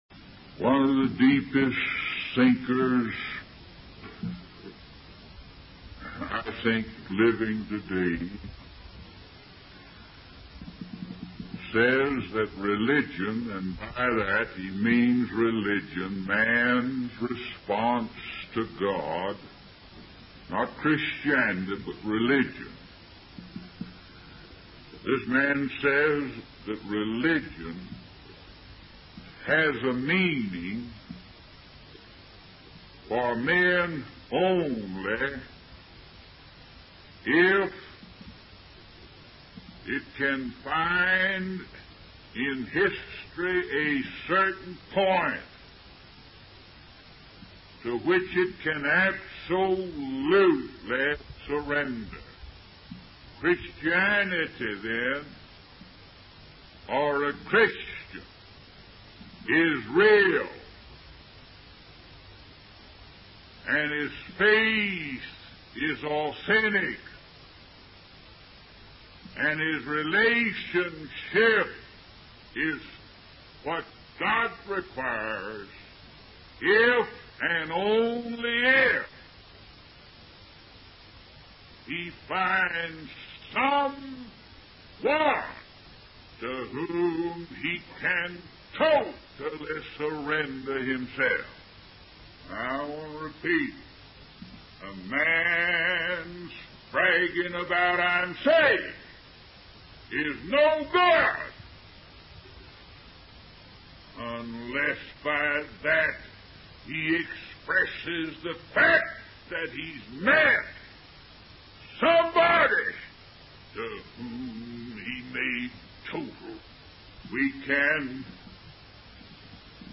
In this sermon, the speaker expresses a deep concern about the state of faith and Christianity in society today. He calls for a reexamination of the message being preached and a return to the true meaning of faith.